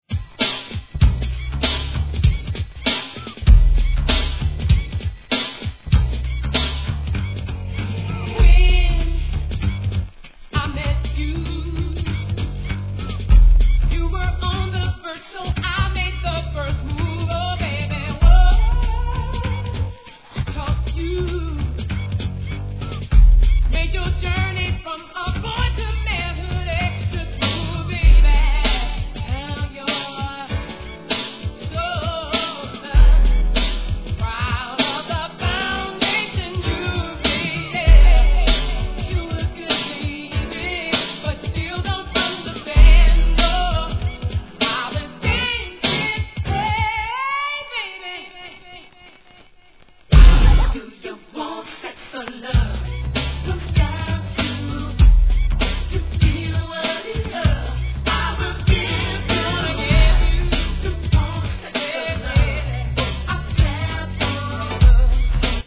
HIP HOP/R&B
1992年のMIDテンポの跳ね物です。